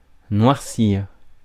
Ääntäminen
US : IPA : [ˈblæk.ən]